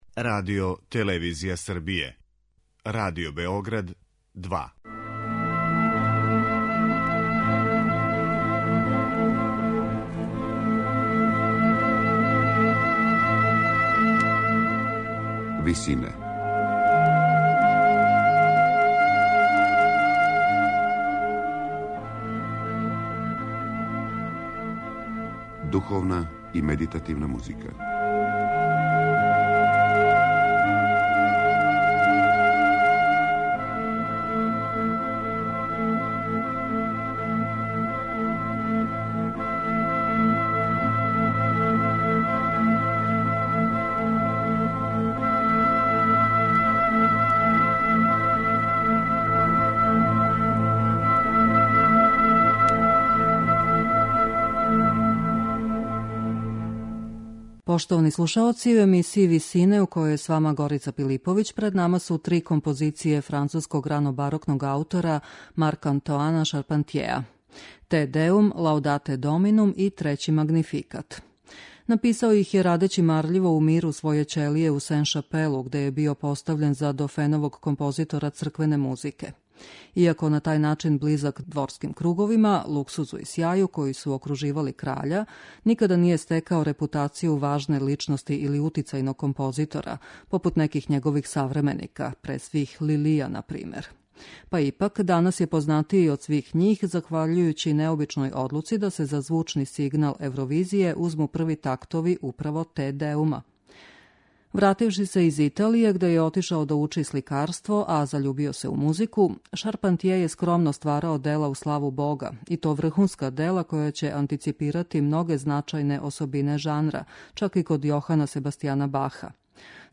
Три црквене композиције Шарпантјеа
медитативне и духовне композиције